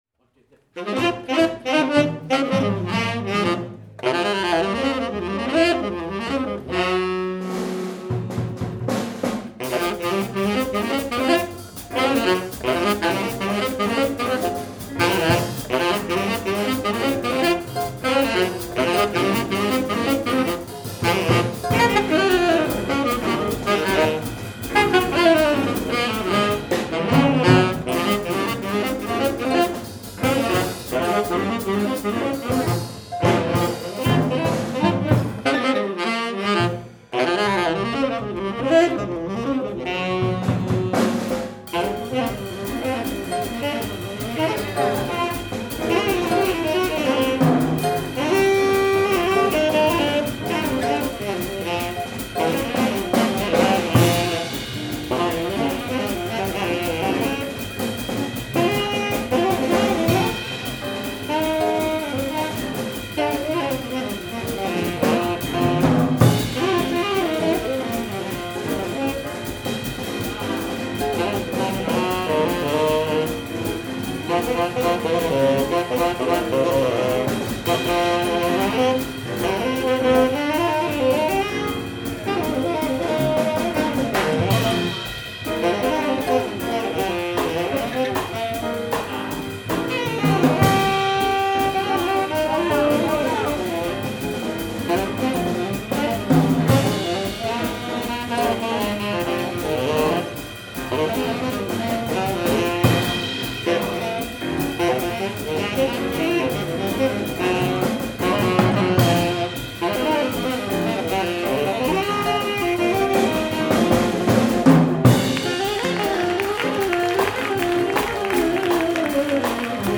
ensembles-jazz-gillespie_bebop.mp3